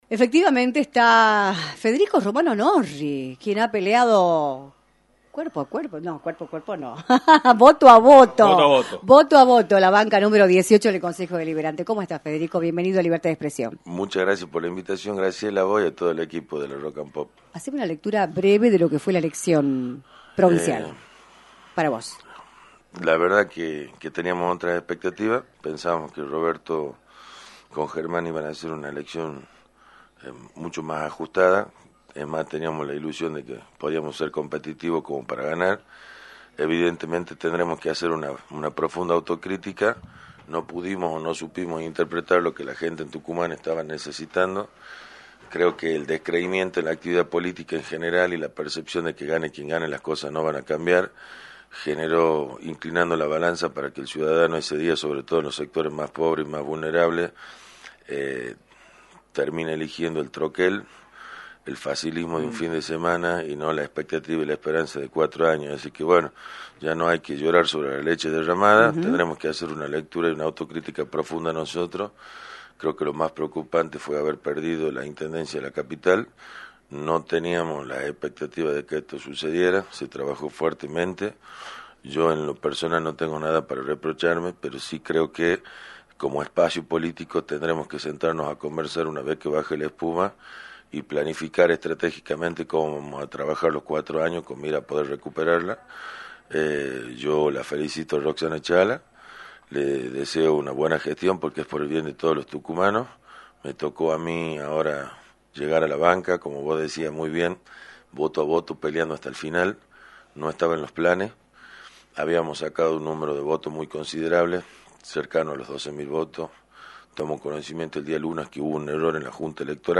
Federico Romano Norri, Concejal electo de San Miguel de Tucumán, visitó los estudios de “Libertad de Expresión”, por la 106.9, para analizar el escenario político de la provincia, luego de que se conocieran los resultados del escrutinio definitivo en la Capital.
entrevista